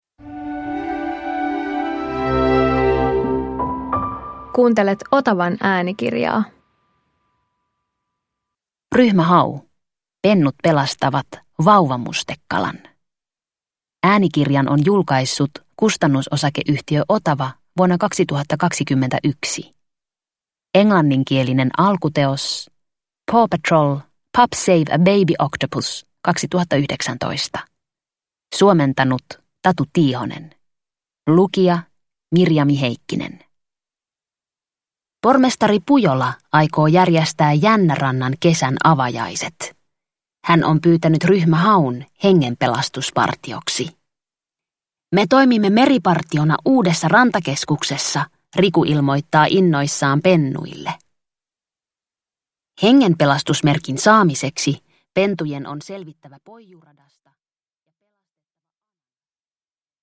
Ryhmä Hau - Pennut pelastavat vauvamustekalan – Ljudbok – Laddas ner